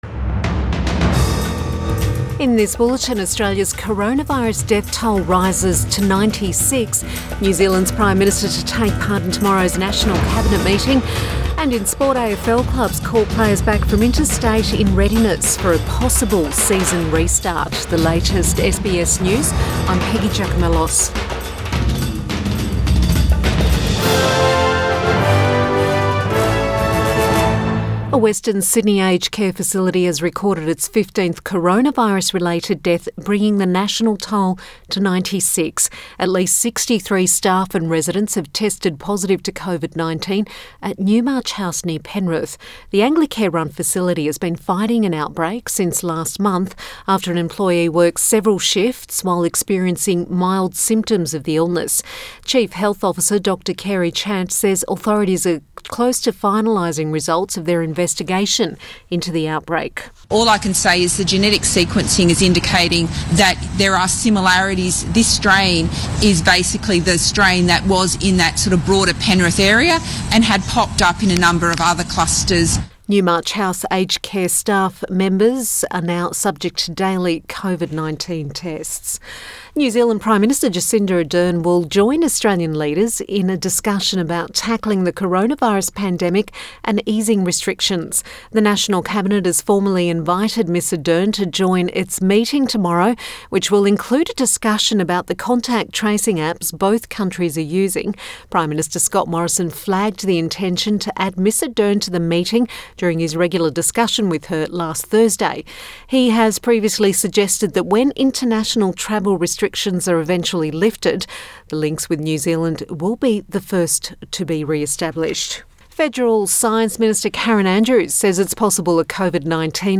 PM bulletin May 4 2020